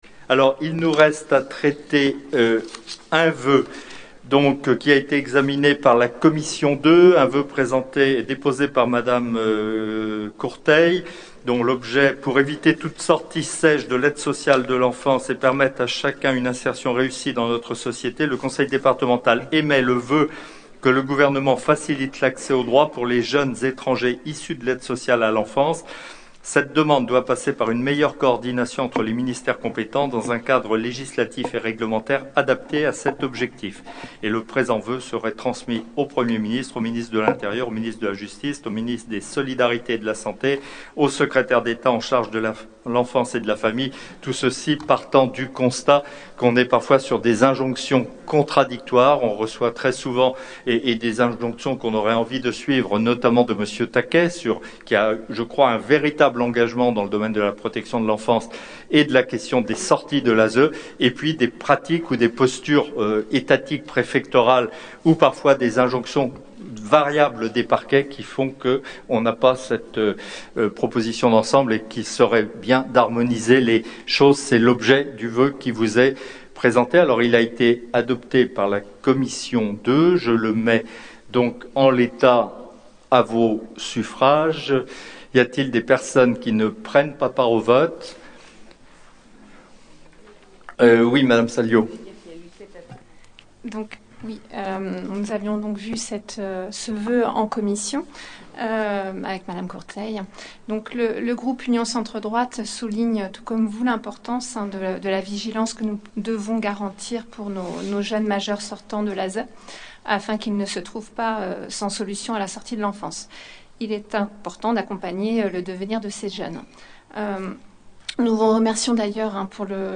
Nature Assemblée départementale